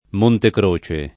m1nte kre] top. — nome, fra l’altro, di due passi alpini: Monte Croce Carnico [m1nte kre k#rniko], Monte Croce di Comelico [m1nte kre di kom$liko] — sim. i cogn. Montecroce, Montecroci